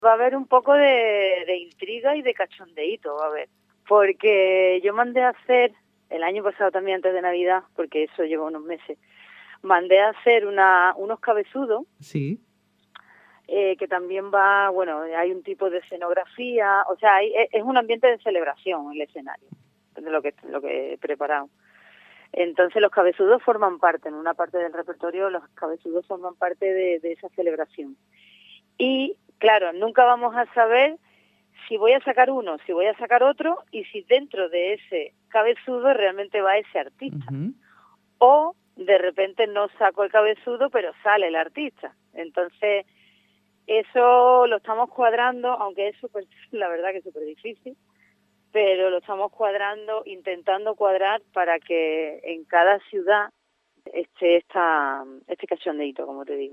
La Mari, cantant de Chambao